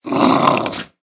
Minecraft Version Minecraft Version 1.21.5 Latest Release | Latest Snapshot 1.21.5 / assets / minecraft / sounds / mob / wolf / sad / growl3.ogg Compare With Compare With Latest Release | Latest Snapshot
growl3.ogg